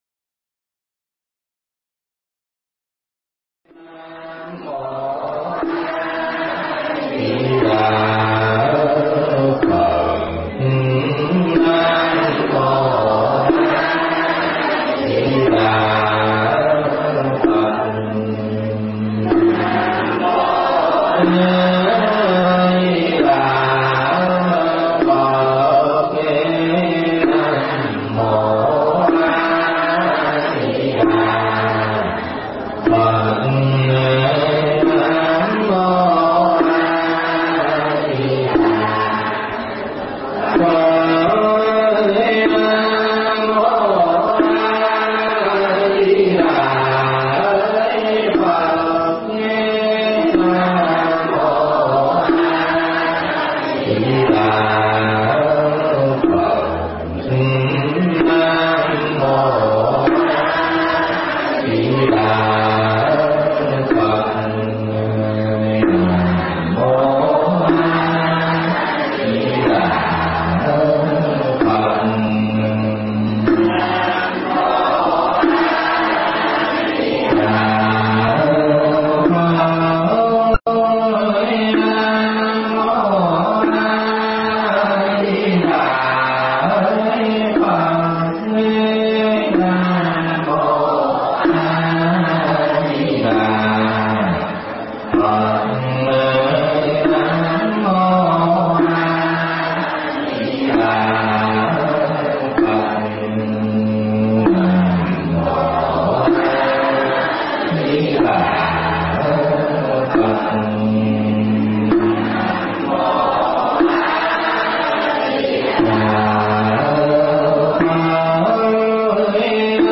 Mp3 Pháp Thoại Biết Nghe Khéo Tu Mới Được Giải Thoát Phần 1
giảng tại Chùa Phước Linh